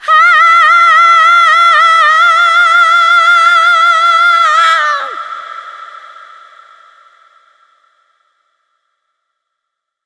SCREAM 6  -R.wav